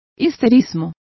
Complete with pronunciation of the translation of hysteria.